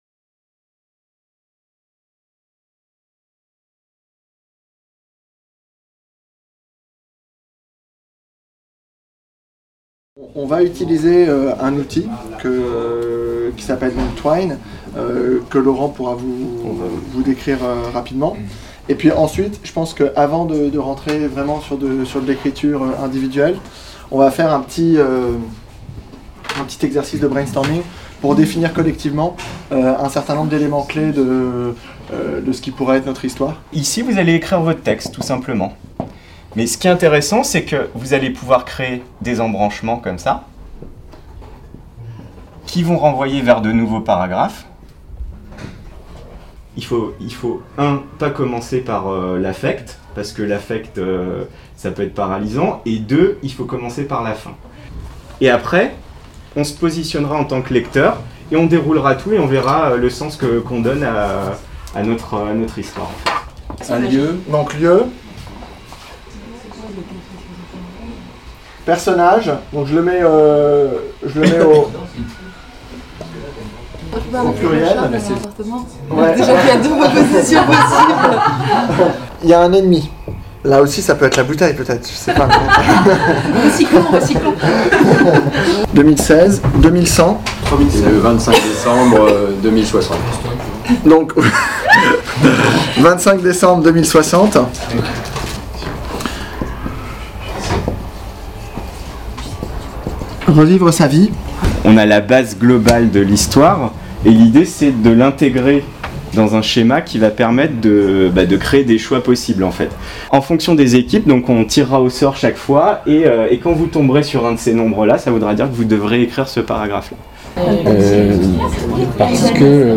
Le numérique, la biotechnologie et la littérature dystopique - Atelier écriture/débat | Canal U